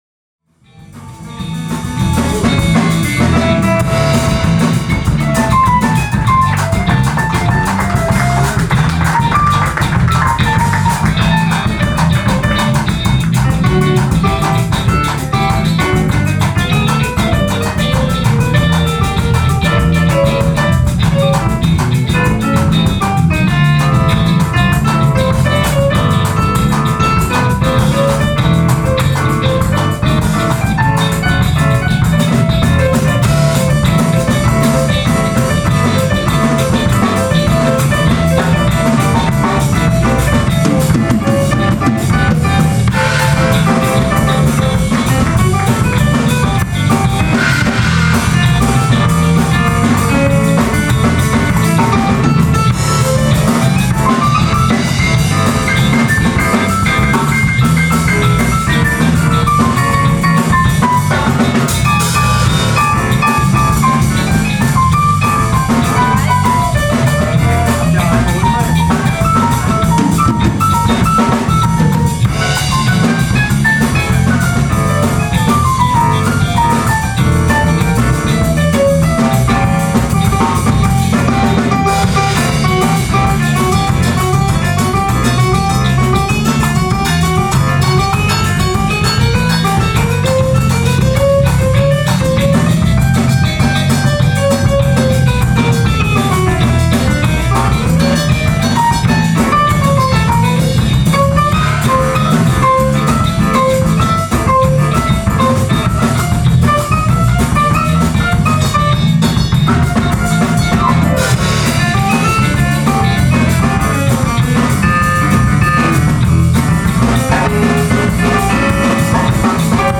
Bass
Vocals, Piano
Flöte, Tenorsaxophon, Sopransaxophon
Gitarre
Percussion, Drums
funky-tropische Eigenkompositionen
MPB (Musica popular brasileira)
elegante Bossanovas